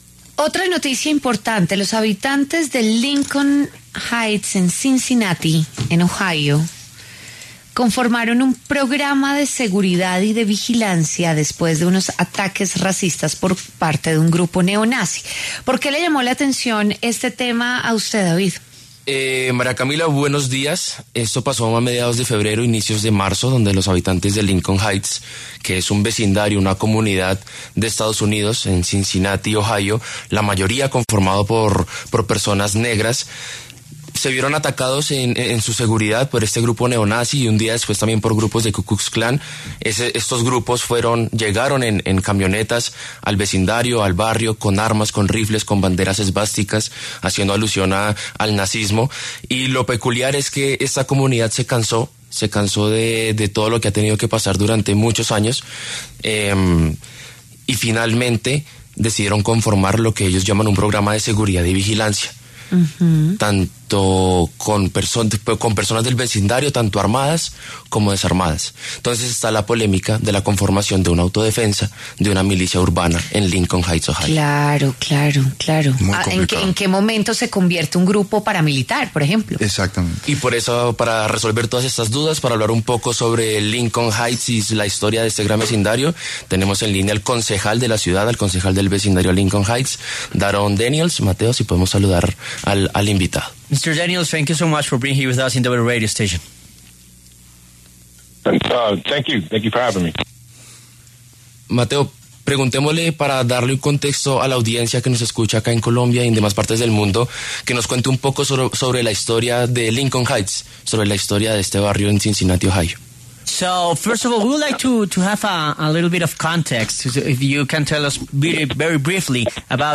Daronce Daniels, concejal de Lincoln Heights (EE.UU.), se refirió en W Fin de Semana al programa de seguridad y vigilancia conformado por los vecinos del sector tras recibir ataques racistas por parte de un grupo neonazi.